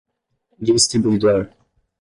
Pronúnciase como (IPA) /d͡ʒis.tɾi.bu.iˈdoʁ/